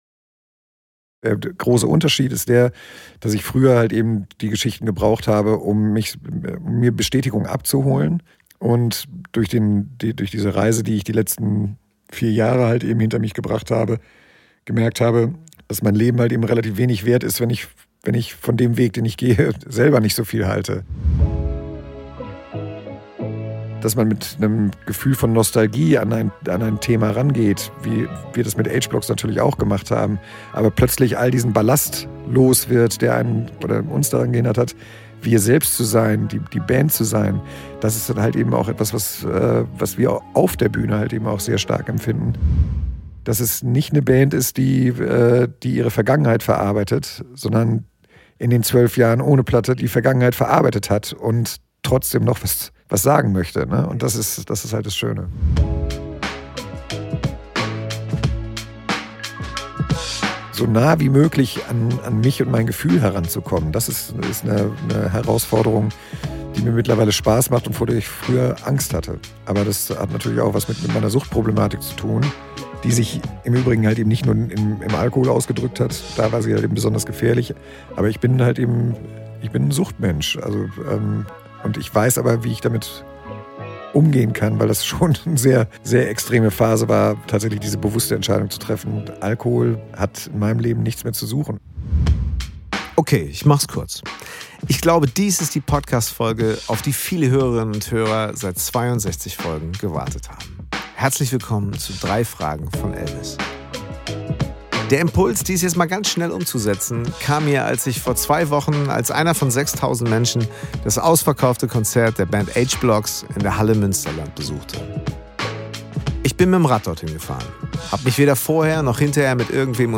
Mein heutiger Gast, Henning Wehland, ist Sänger dieser Band - als Freund und beruflicher Weggefährte wohl eine meine wichtigsten Inspirations- und Lernquellen der vergangenen 30 Jahre.
Ich musste mir für dieses Gespräch nicht wirklich Fragen überlegen, denn mein heutiger Gast Henning Wehland geht mit seinen eigenen Fragestellungen derart offen, reflektiert und vor allem inspirierend um, dass ich einfach mal zuhören durfte.